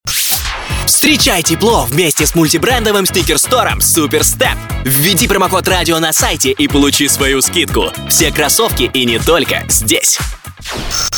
Муж, Рекламный ролик/Молодой